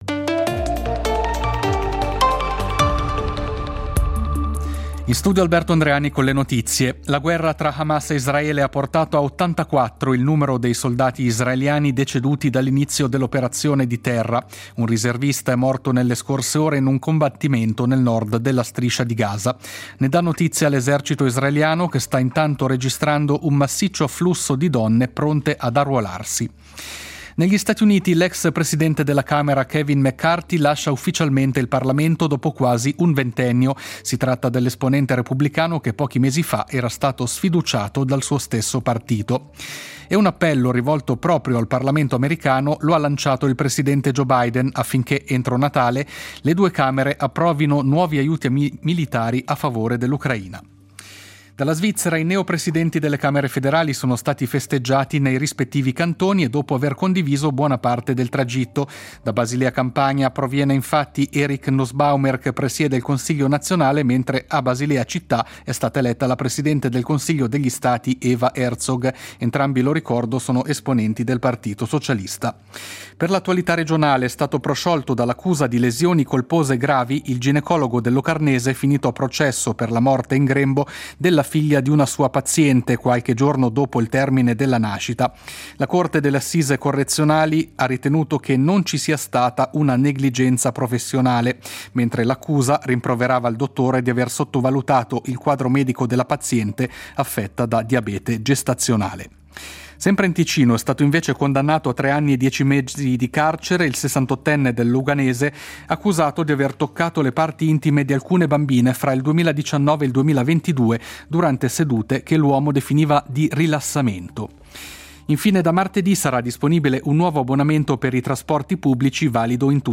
Notiziario delle 20:00 del 06.12.2023